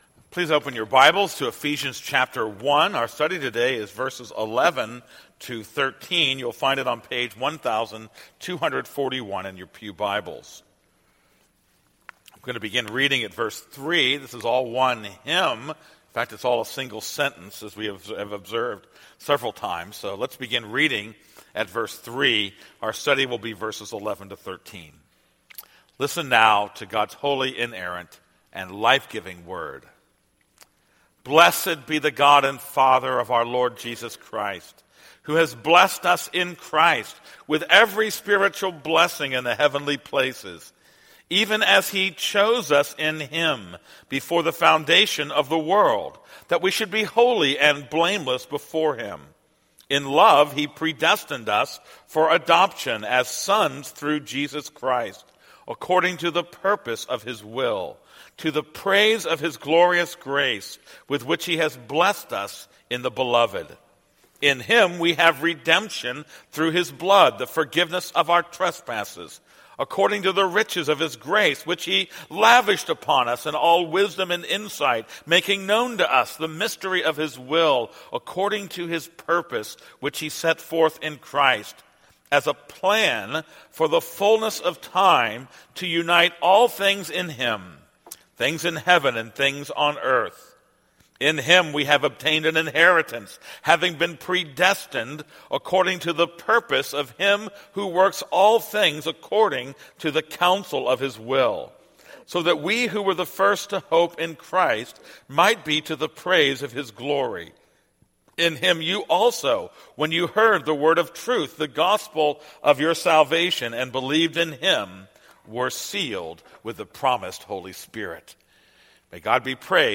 This is a sermon on Ephesians 1:11-13.